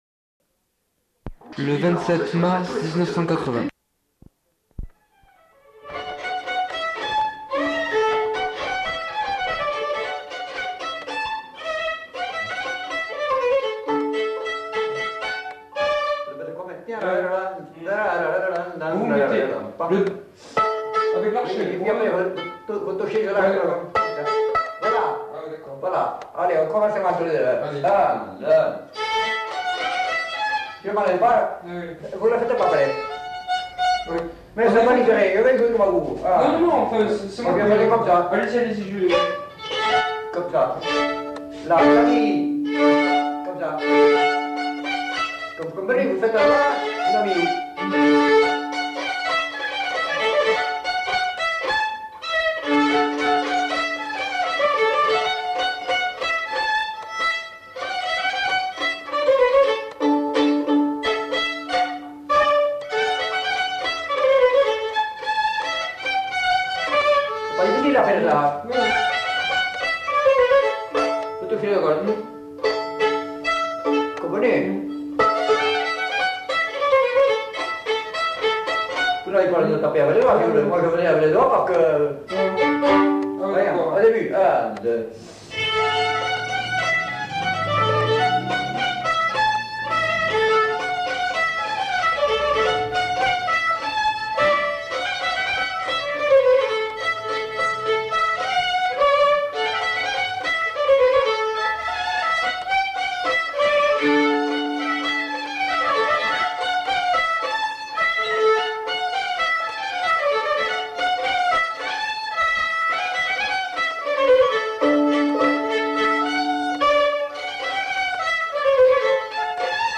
Lieu : Casteljaloux
Genre : morceau instrumental
Instrument de musique : violon
Danse : rondeau